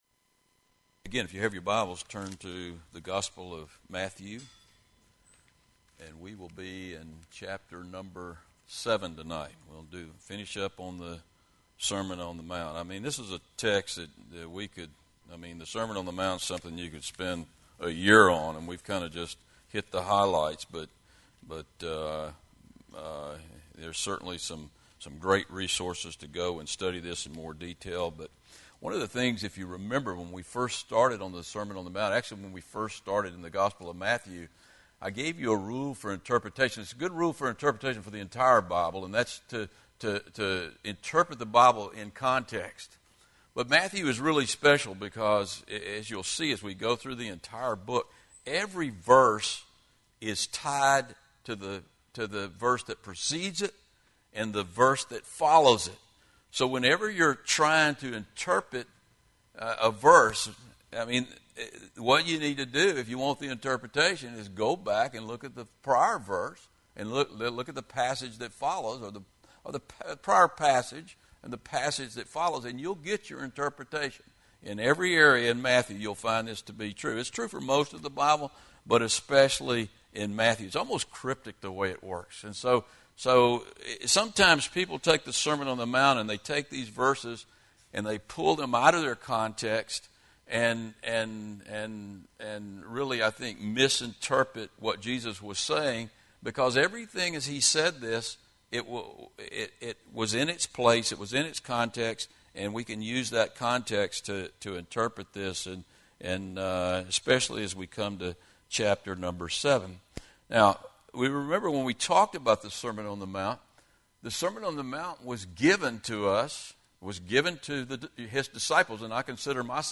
From Wednesday evening service.